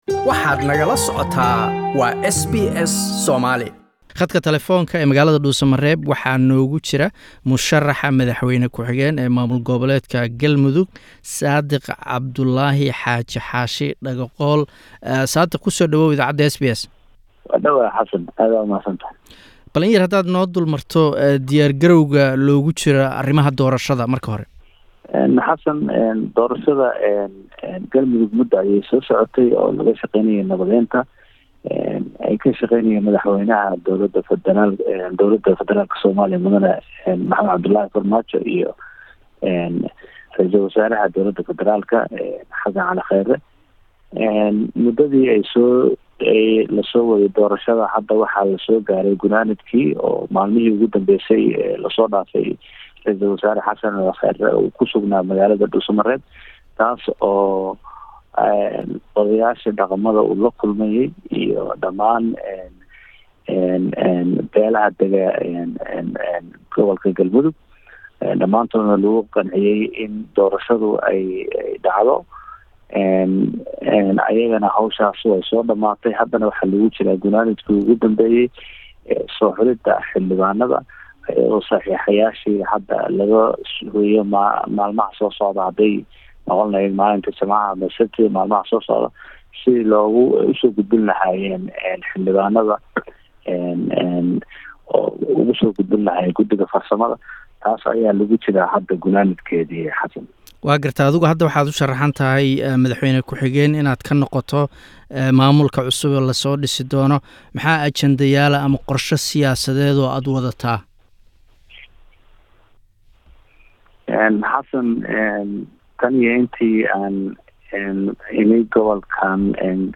Waraysi